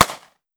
9mm Micro Pistol - Gunshot A 005.wav